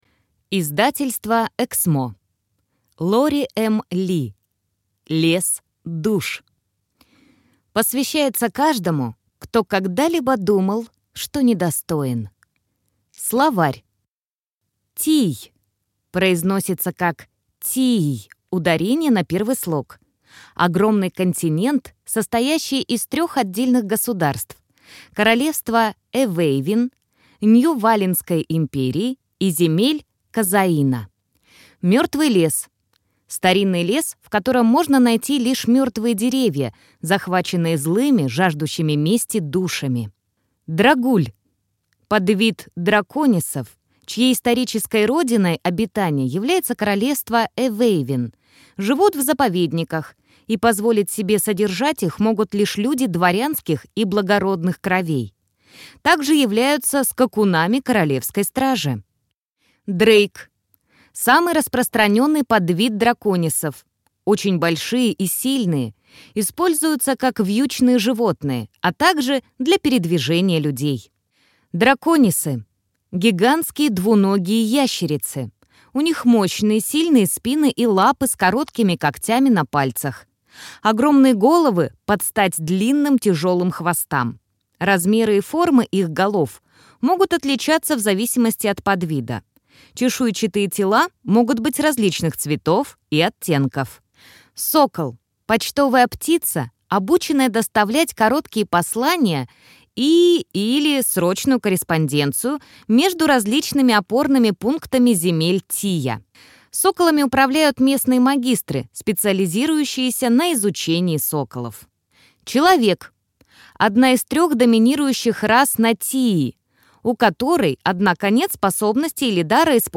Аудиокнига Лес душ | Библиотека аудиокниг